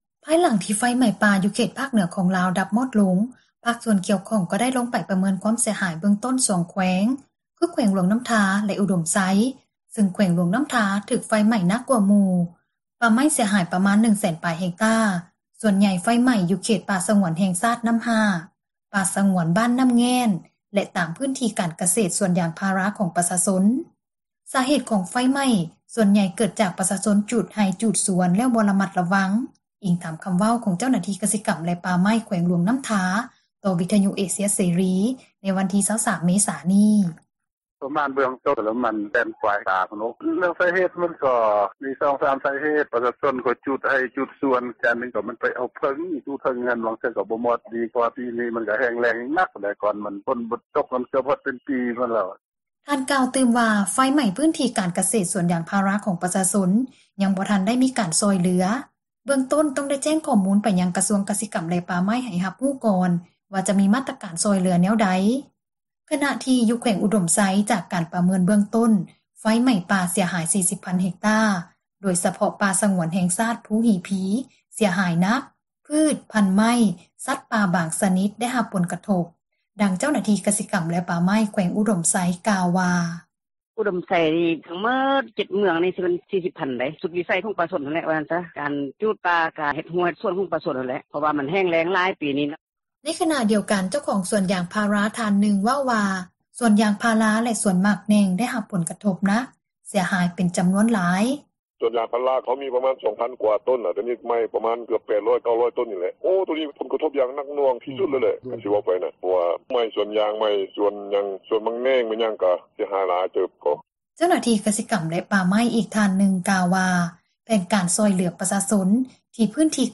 ໃນຂນະດຽວກັນ ເຈົ້າຂອງສວນຢາງພາຣາ ທ່ານນຶ່ງເວົ້າວ່າ ສວນຢາງພາຣາ ແລະສວນໝາກແໜ່ງ ໄດ້ຮັບຜົລກະທົບໜັກ ເສັຍຫາຍເປັນ ຈໍານວນຫຼາຍ:
ເຈົ້າໜ້າທີ່ ກະສິກັມແລະປ່າໄມ້ ອີກທ່ານນຶ່ງ ກ່າວວ່າ ແຜນການຊ່ອຍເຫຼືອປະຊາຊົນ ທີ່ ພື້ນທີ່ການກະເສດຖືກໄຟໄໝ້ ທາງການກໍາລັງ ຮິບໂຮມຂໍ້ມູນວ່າມີພື້ນທີ່ ເສັຍຫາຍເທົ່າໃດ: